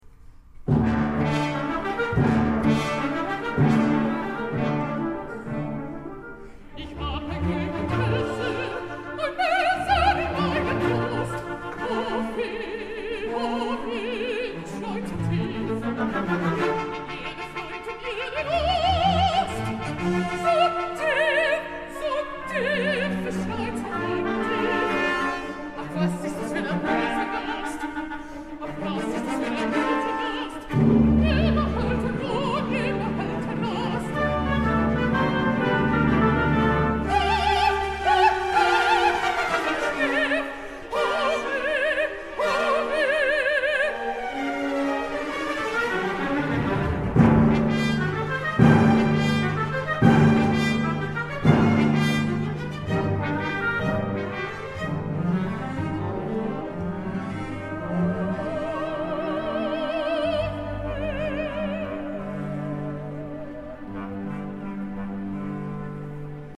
Disfruta de esta actuación de la Orquesta Sinfónica Juan José Landaeta, dirigida por el maestro Christian Vásquez, el pasado 12 de Julio de 2025, en nuestro canal de YouTube.
Mezzosoprano Visita el enlace directo en nuestra biografía.